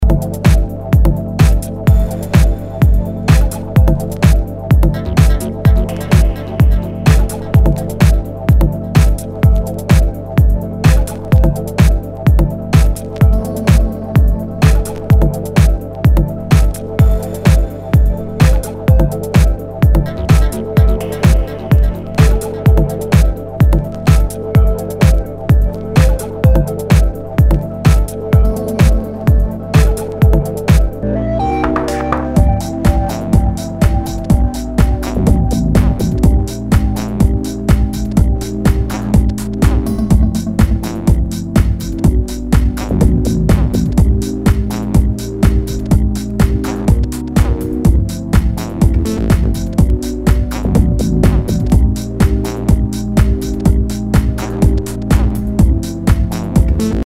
HOUSE/TECHNO/ELECTRO
ナイス！プログレッシブ / ディープ・ハウス！
ジャケにスレキズ、ヨゴレ、角潰れあり。プレイ可能ですが盤に歪みあり。盤にヤケ、傷あり全体にチリノイズが入ります